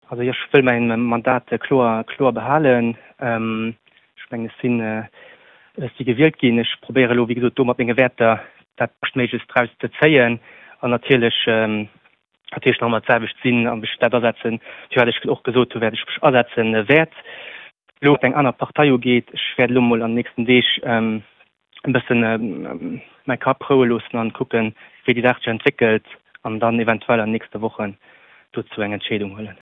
Dem Ben Polidori seng Reaktioun